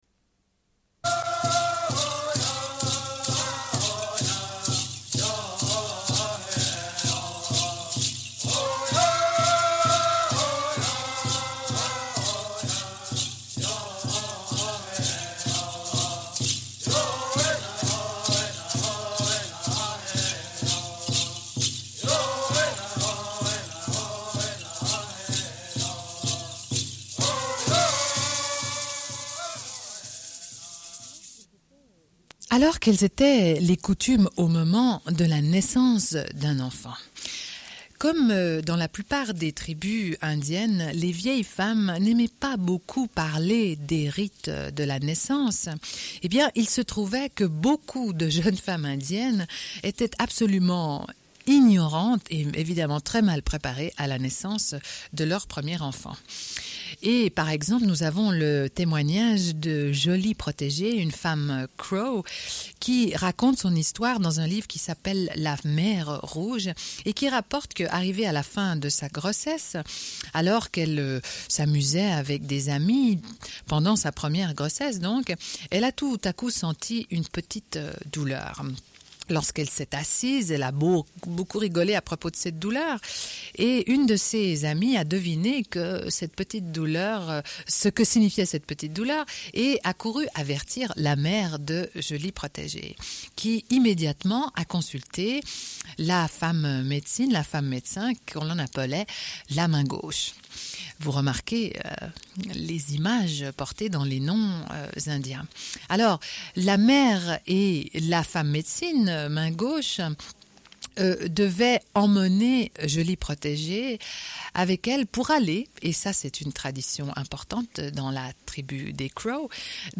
Une cassette audio, face B00:29:47